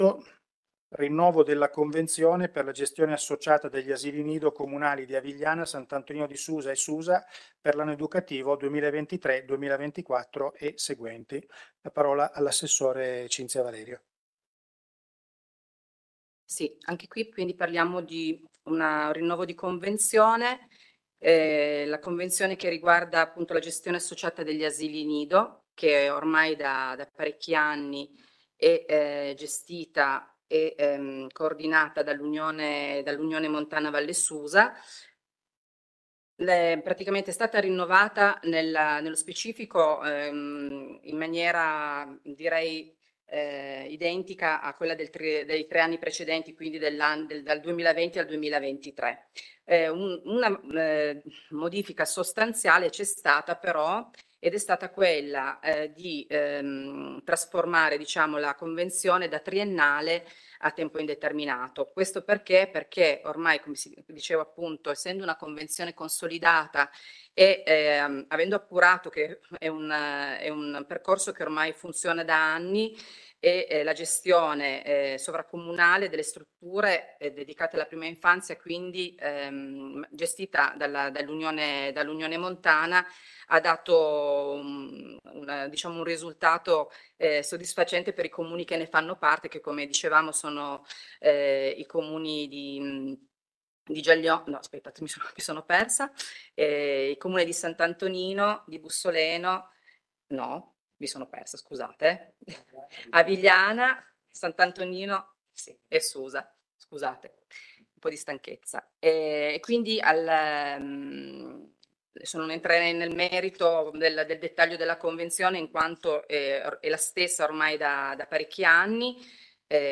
téléchargement de consiglio comunale comune susa 27.07.2023 punto 8 rinnovo convenzione